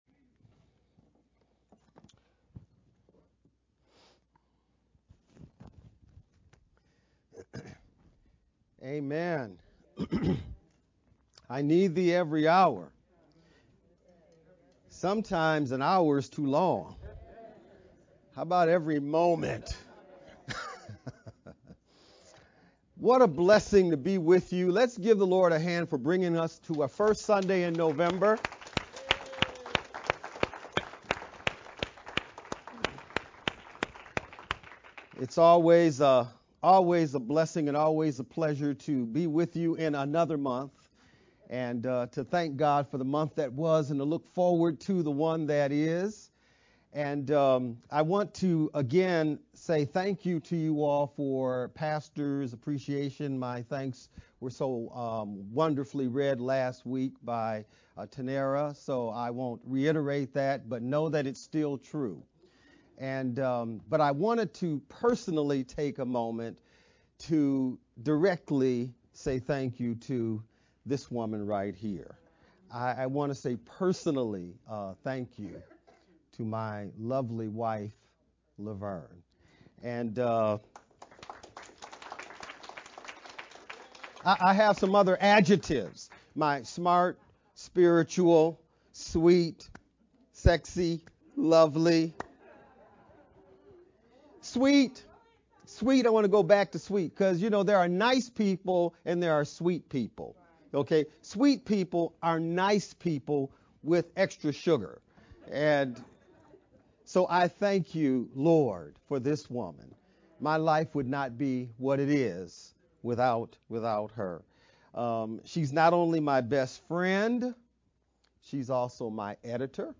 11-6-VBCC-Sermon-edited-sermon-only-CD.mp3